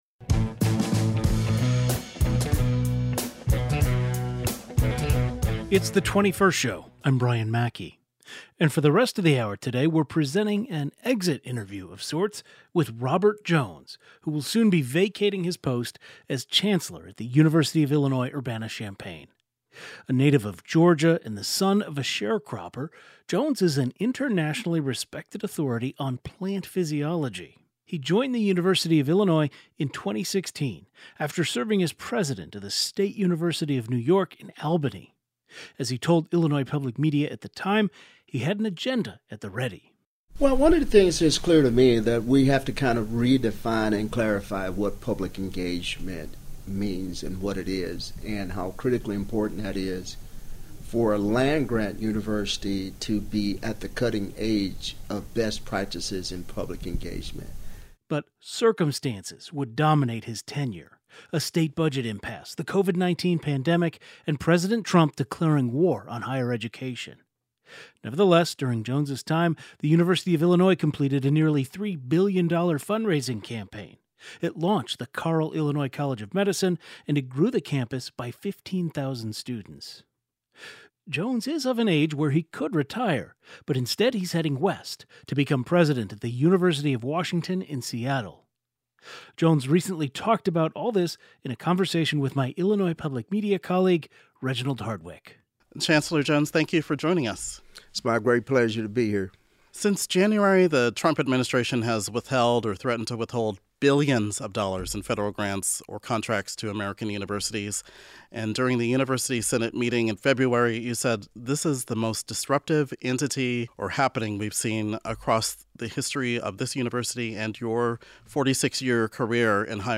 A conversation with outgoing U of I Chancellor Robert Jones